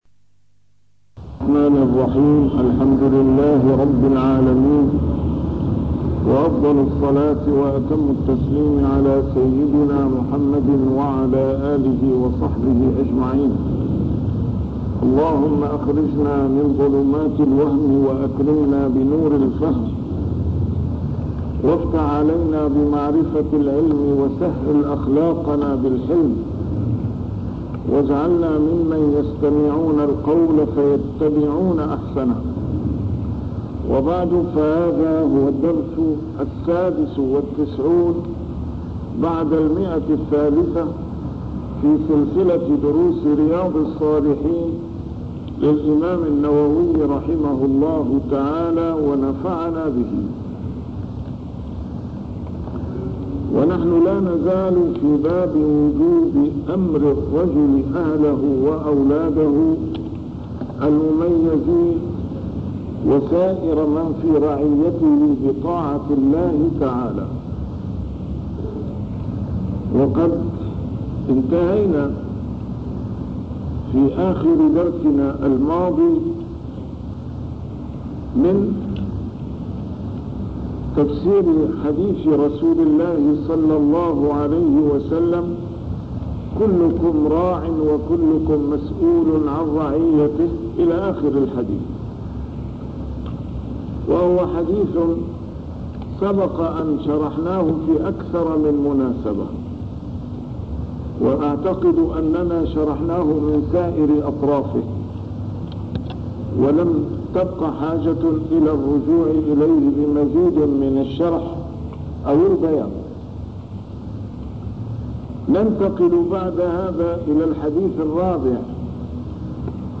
A MARTYR SCHOLAR: IMAM MUHAMMAD SAEED RAMADAN AL-BOUTI - الدروس العلمية - شرح كتاب رياض الصالحين - 396- شرح رياض الصالحين: أمر الرجل أهله بطاعة الله